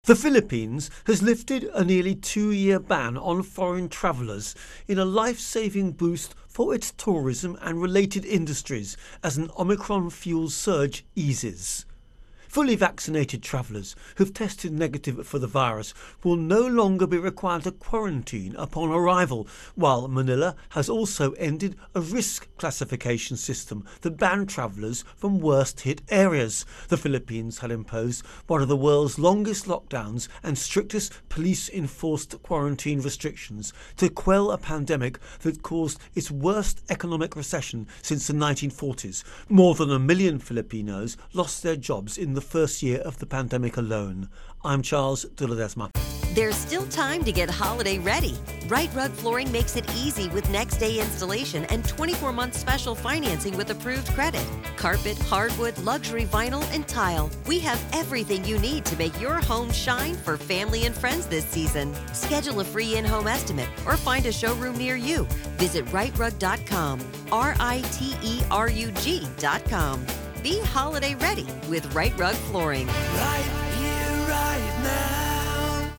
Virus Outbreak-Philippines Intro and Voicer